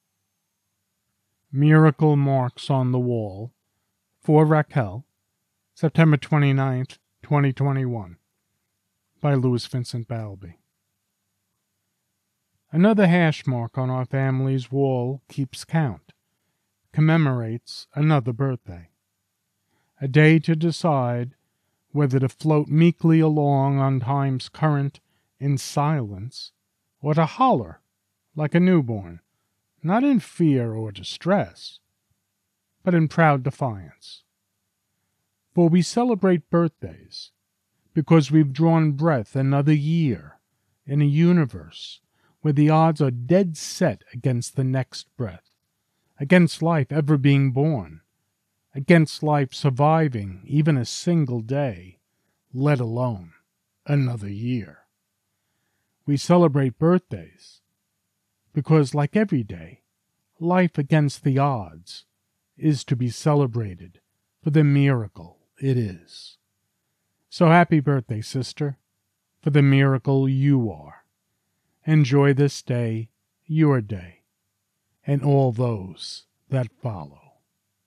Miracle Marks on the Wall Poem